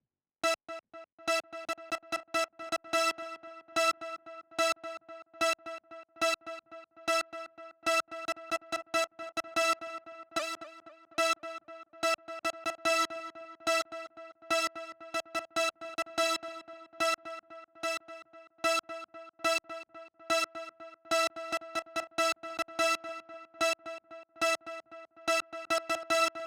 02 pulse lead A.wav